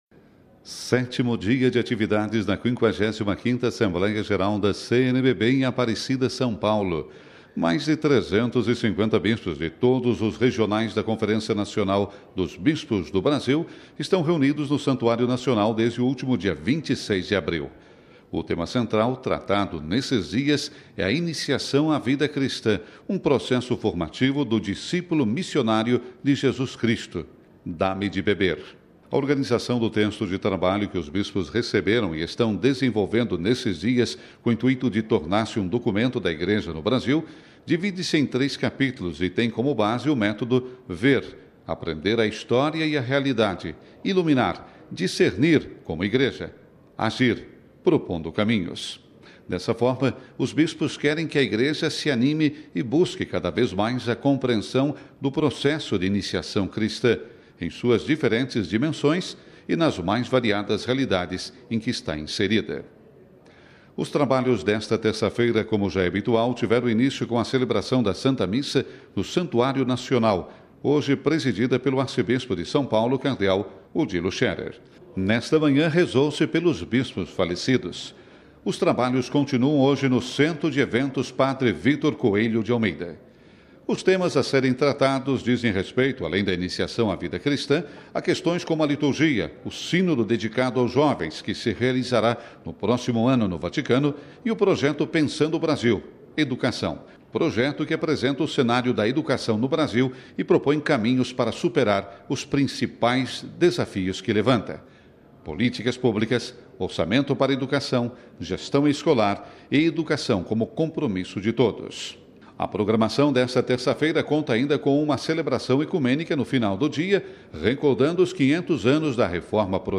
Ele conversou conosco...